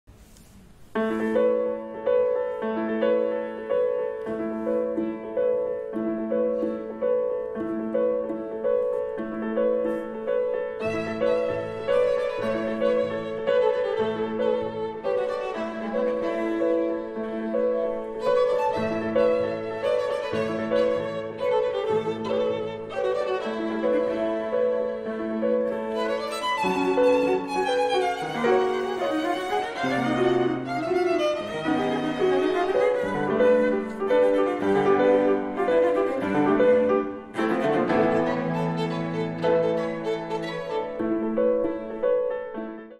Violon, Violoncelle et Piano
vingt miniatures acoustiques